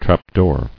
[trap·door]